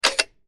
Photo-shutter-sound.mp3